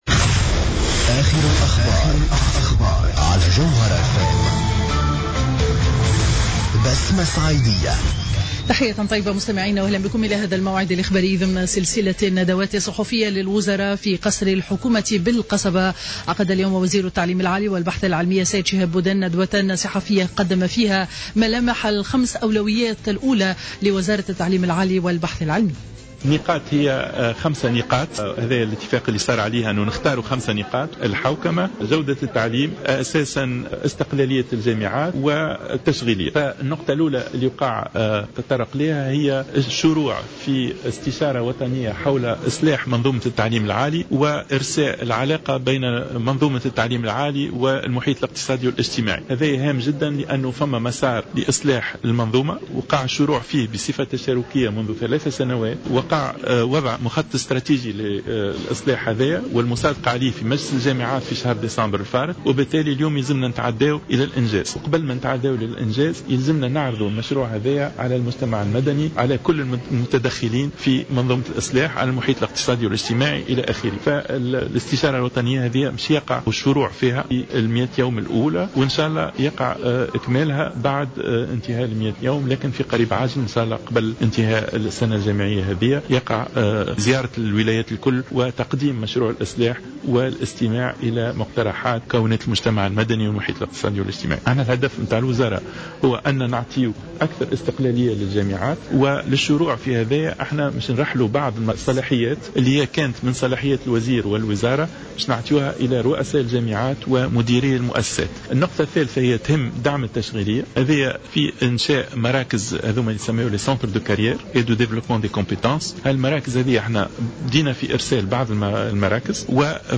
نشرة أخبار منتصف النهار ليوم الثلاثاء 31 مارس 2015